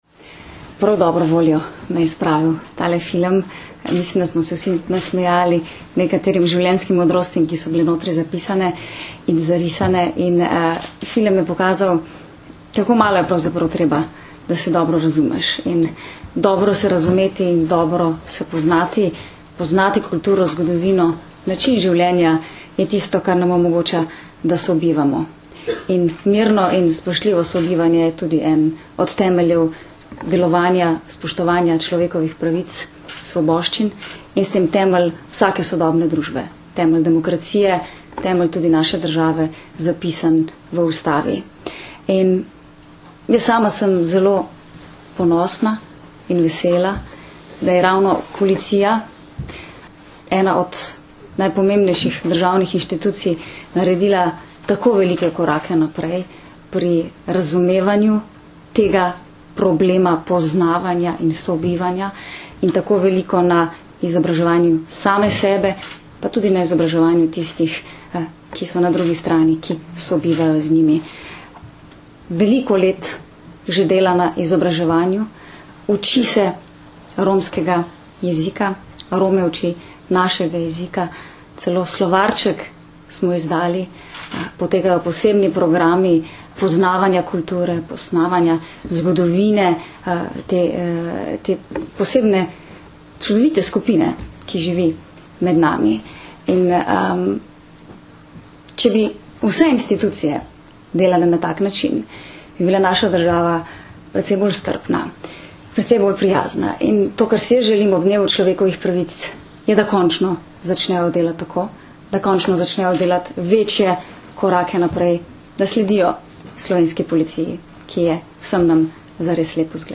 Zvočni posnetek nagovora Katarine Kresal (mp3)